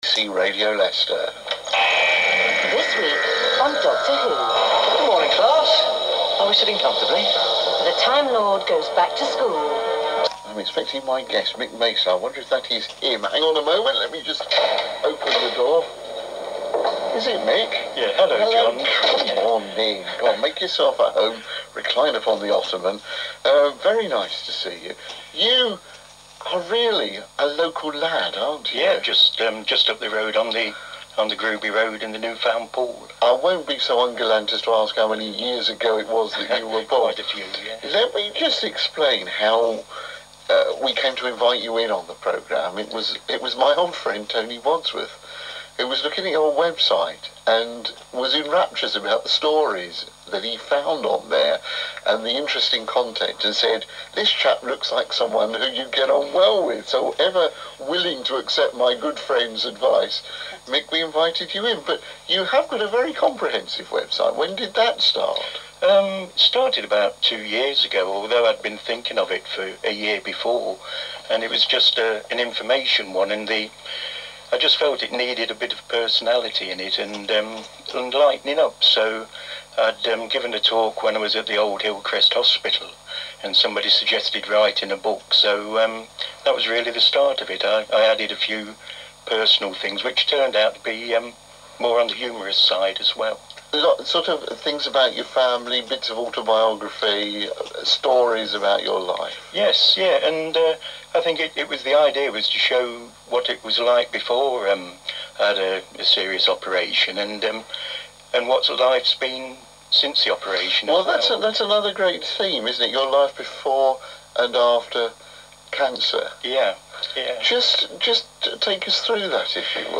Includes Choir at St. Leonard's and St. Augustine's Churches
Radio Leicester  interview.mp3